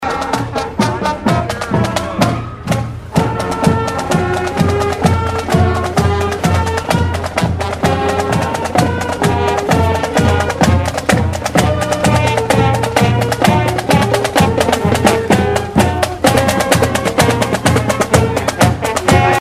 The Riley County High School Marching Band flawlessly performed throughout the parade; with equipment from the Leonardville Fire Station coming behind them with lights and occasional sirens.